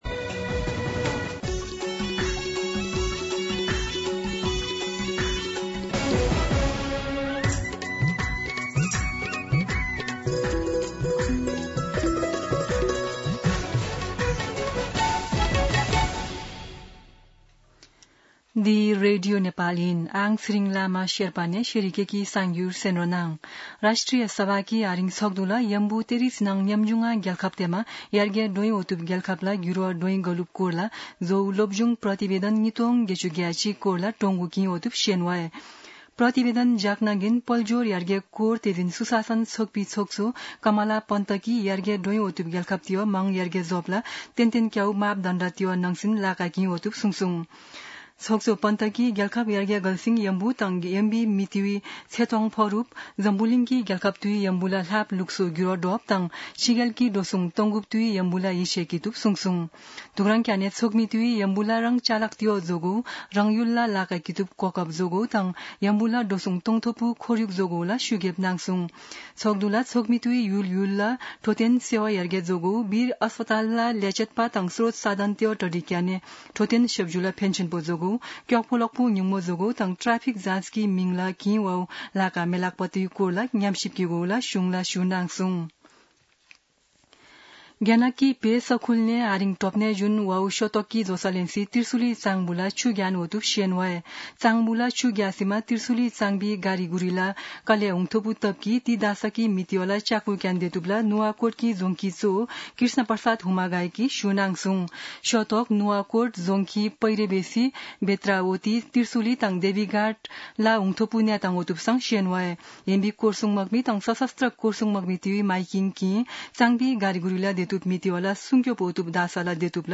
शेर्पा भाषाको समाचार : १४ साउन , २०८२
Sherpa-News-04-14.mp3